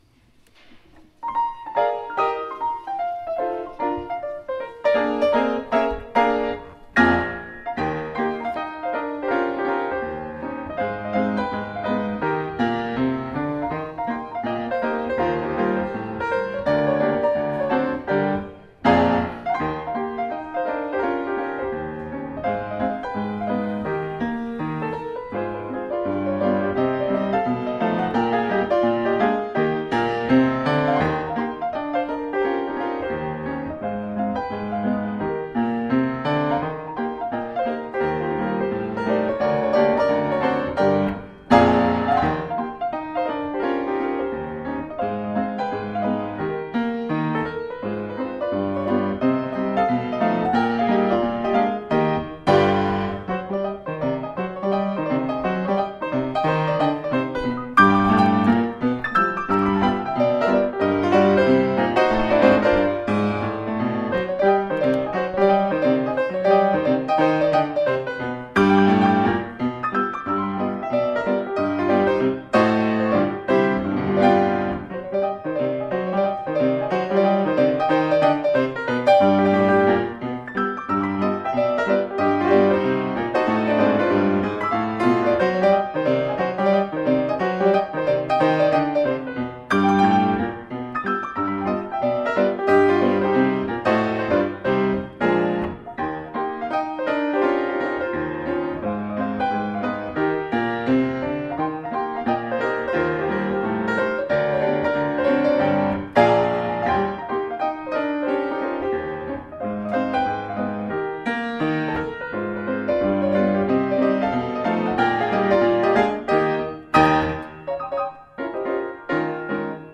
This is a style or genre known as *novelty piano*, first developed by